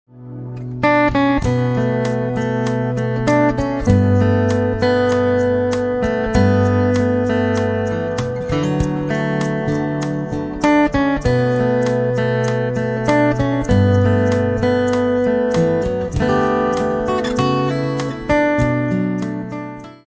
chitarra acustica, percussioni.
basso elettrico, basso fretless.